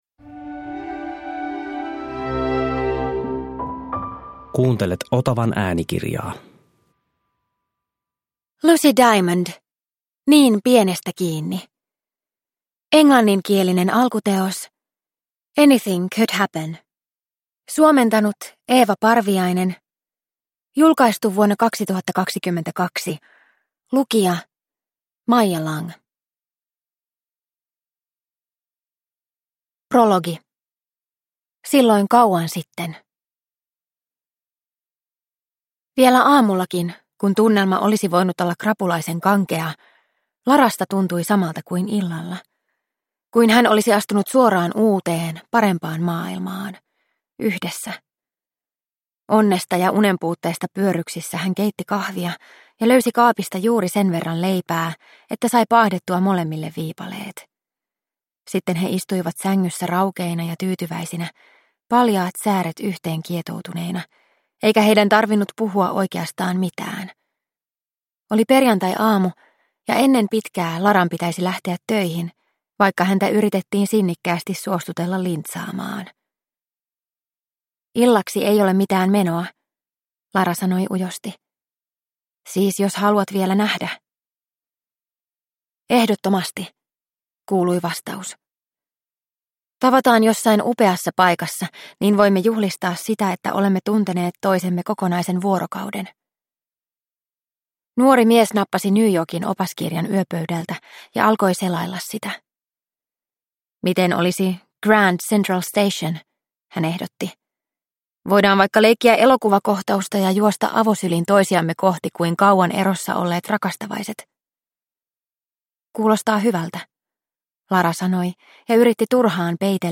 Niin pienestä kiinni – Ljudbok – Laddas ner